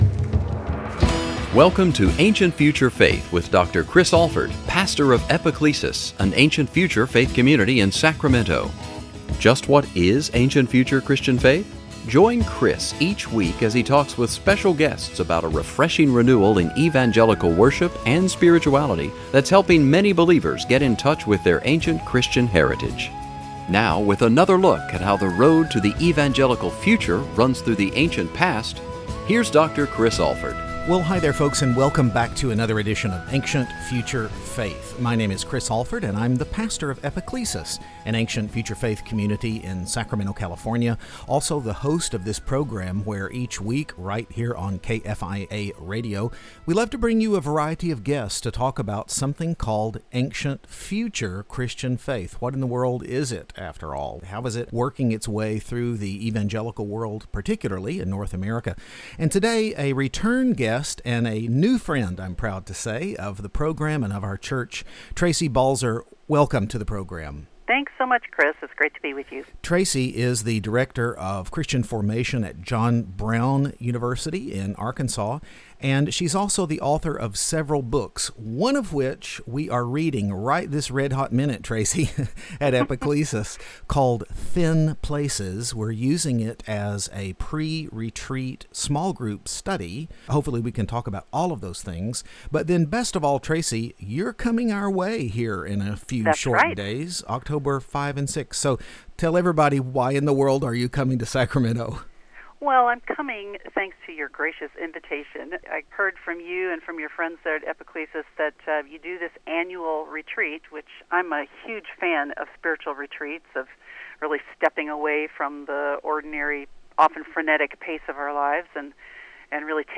What is a "thin place" (or a "thick" one, for that matter), and could your prayer life use some refreshing? Join us for this conversation about the rich, Christian spirituality of the Celts.